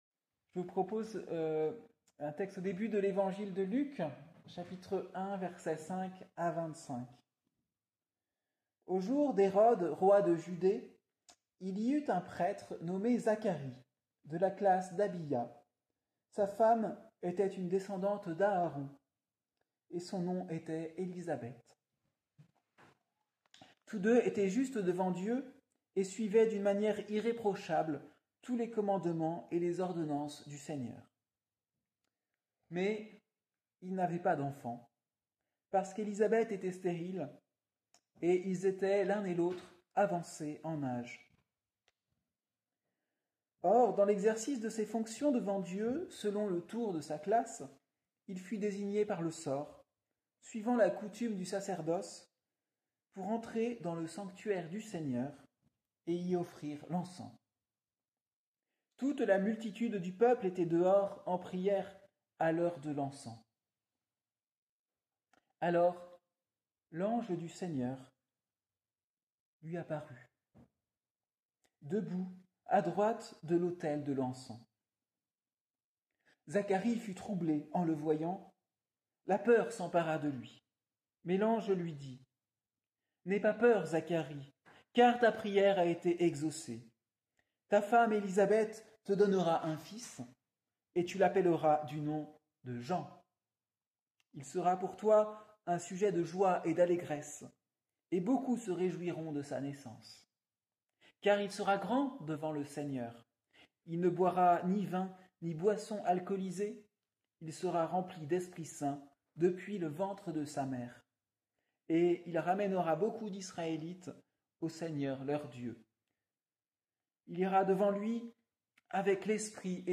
Prédication du dimanche 1er décembre 2025, premier dimanche de l'Avent